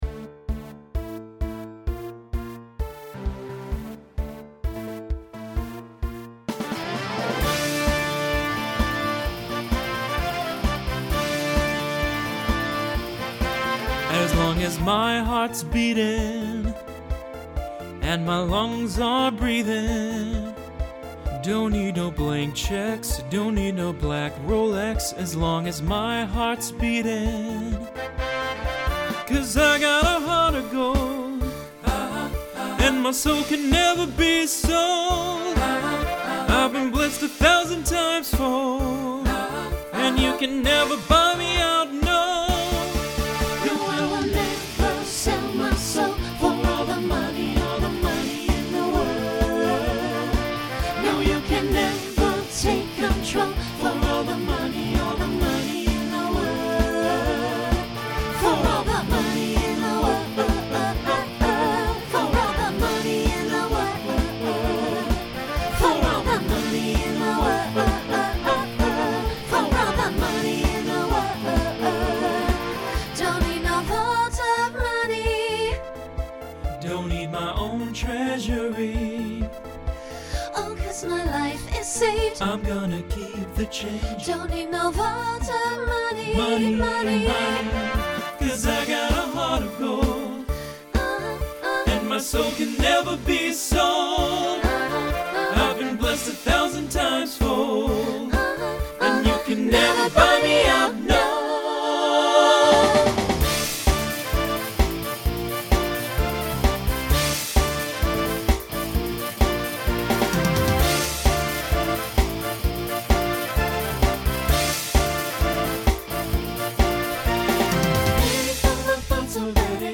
SATB SSA